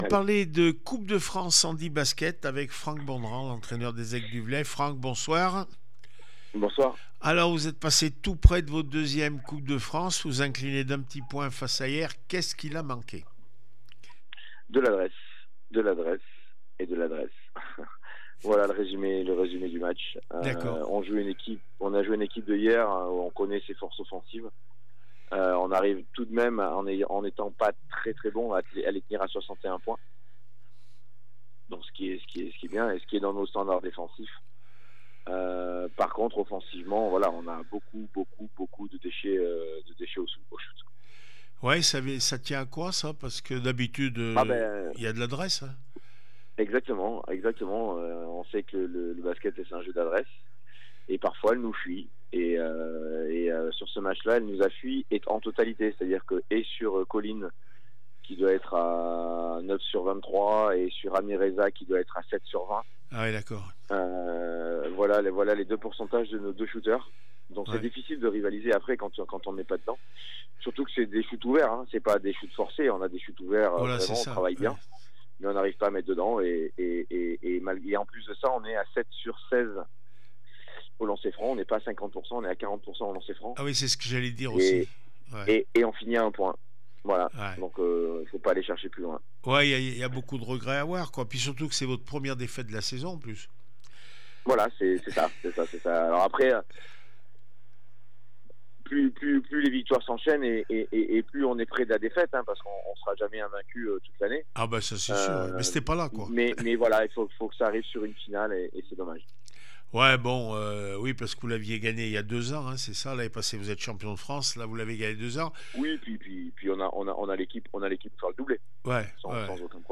handi basket coupe de France Hyères 61-60 les aigles du Velay réaction après match